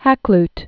(hăklt), Richard 1552?-1616.